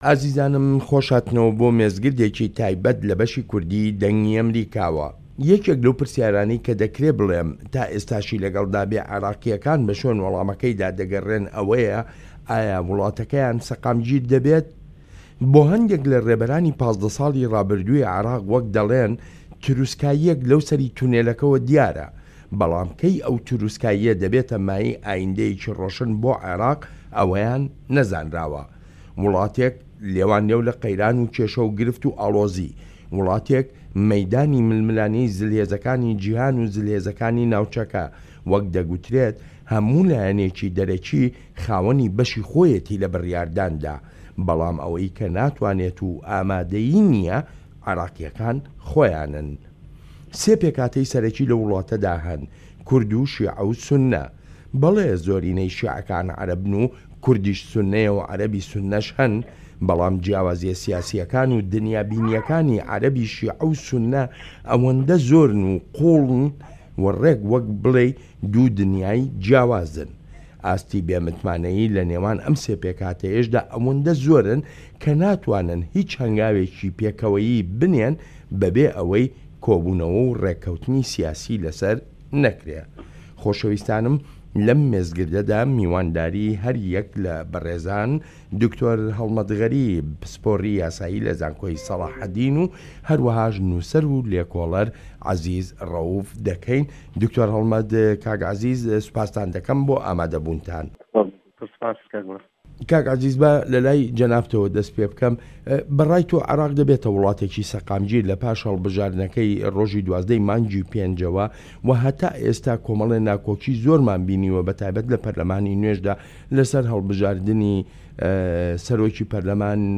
مێزگرد: عێراق لە گێژاوی نادیاریی ئایندەیدا